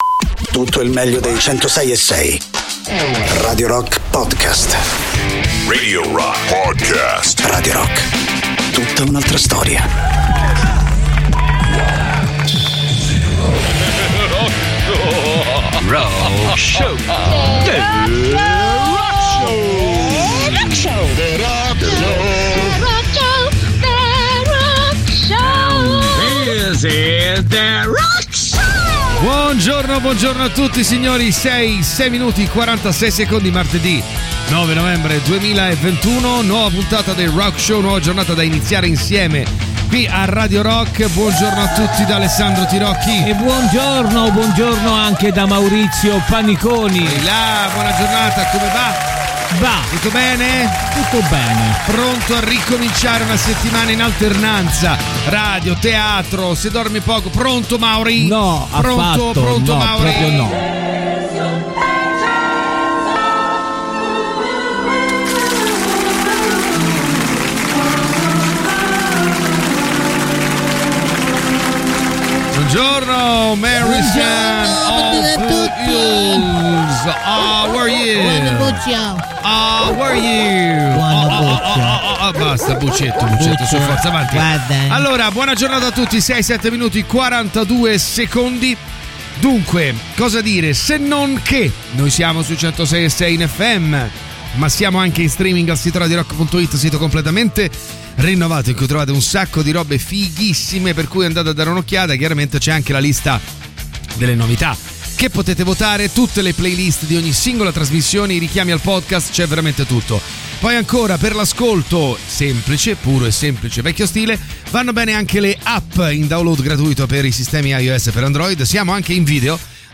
in diretta dal lunedì al venerdì